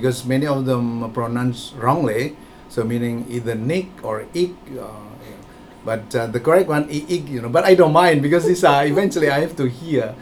S1 = Taiwanese female S2 = Indonesian male Context: S2 is discussing the pronunciation of a name.
There is minimal aspiration on the initial consonant of correct -- the VOT is about 23 ms, so S1 heard it as [g] rather than [k]. In addition, there is just a glottal stop rather than the consonant cluster [kt] at the end of the word.